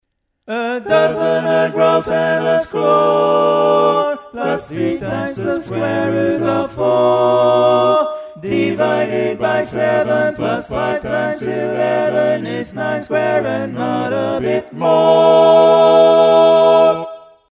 Key written in: E Major
How many parts: 4
Type: Barbershop
All Parts mix: